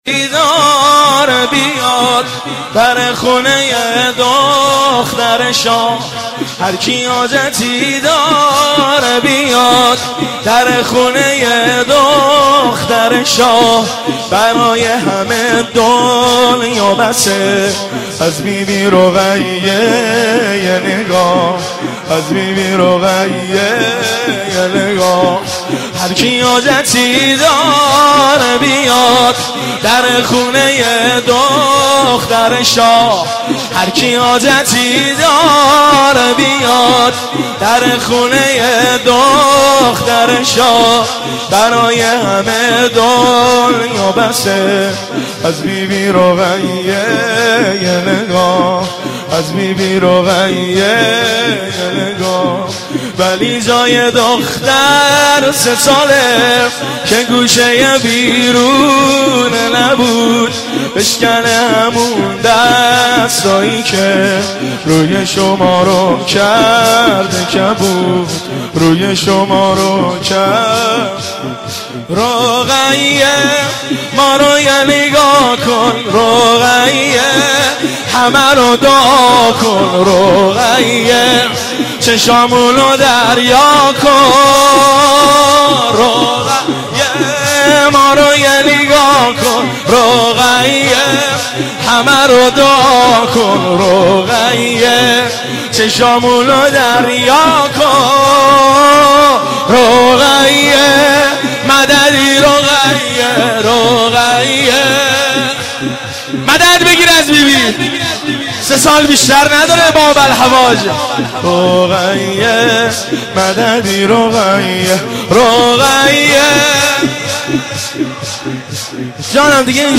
مداحی هر کی حاجتی داره بیاد(شور)
شهادت حضرت رقیه خاتون(علیه السلام) 1391